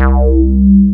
ACIDSQRE.wav